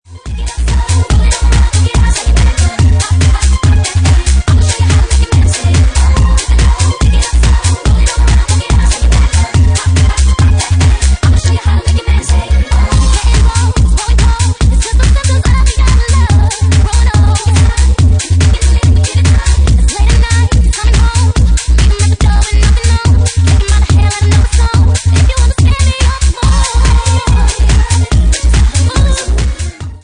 Genre:Bassline House
Bassline House at 72 bpm